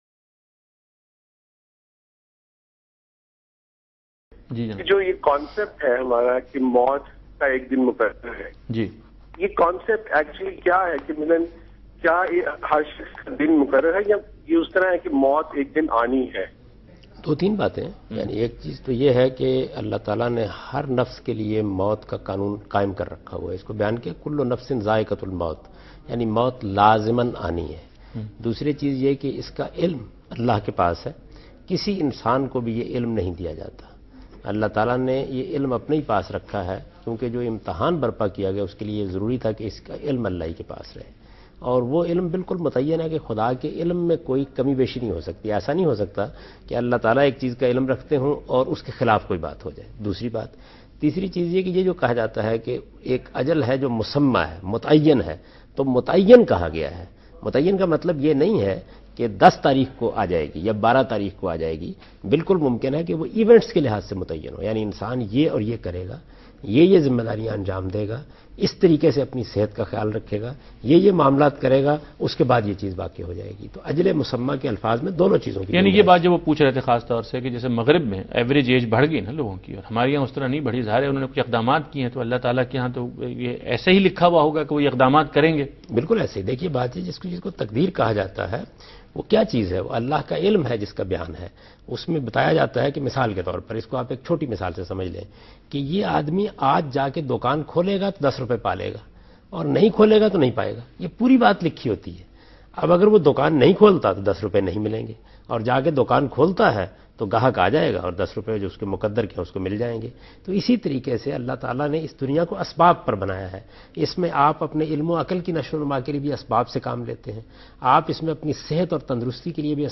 Javed Ahmad Ghamdi answers a question "Is Time of Death Predetermined?" in program Deen o Danish on Dunya News.
جاوید احمد غامدی دنیا نیوز کے پروگرام دین و دانش میں اس سوال "کیا موت کا وقت مقرر ہے؟" کا جواب دیتے ہیں۔